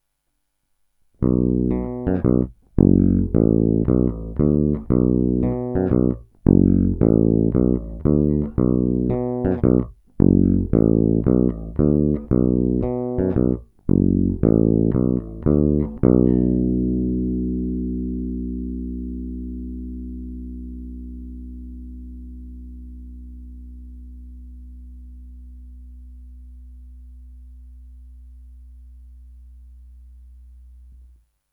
Následující nahrávky, není-li řečeno jinak, jsou provedeny rovnou do zvukové karty, jen normalizovány, jinak ponechány v původním stavu bez postprocesingu. Tónová clona byla vždy plně otevřená.
Kobylkový snímač